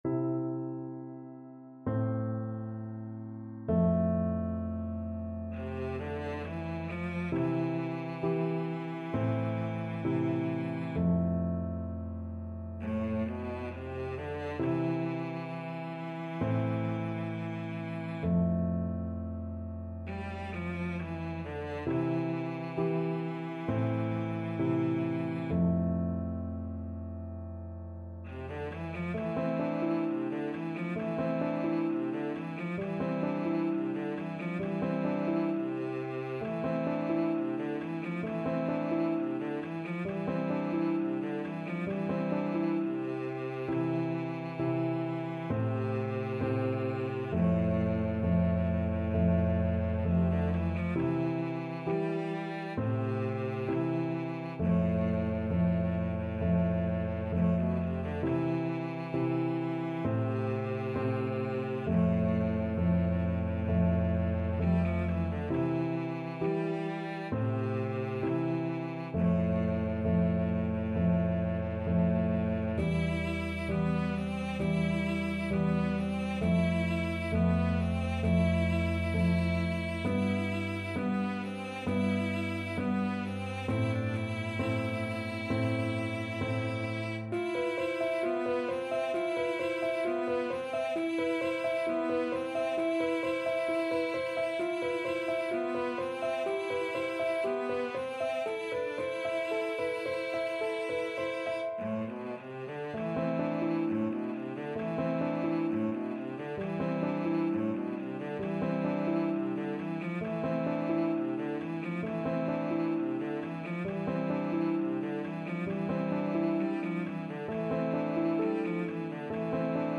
Cello version
4/4 (View more 4/4 Music)
Classical (View more Classical Cello Music)